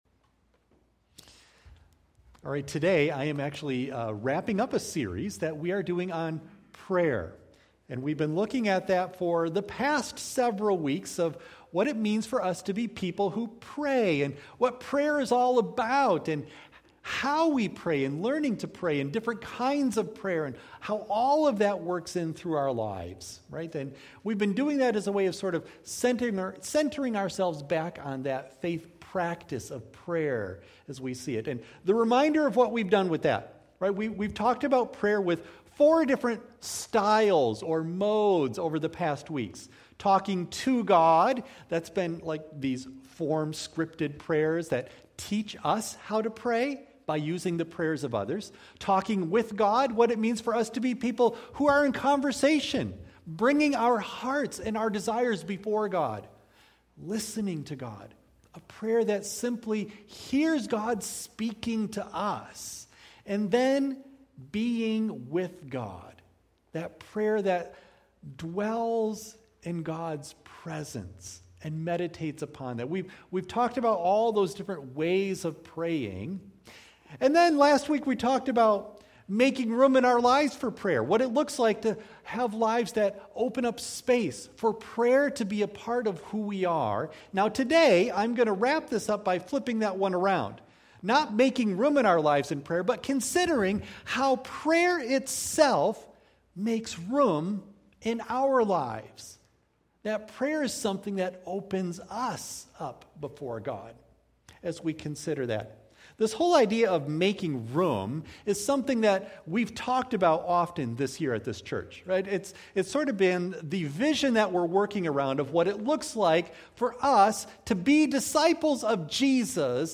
Worship Service
Audio of Message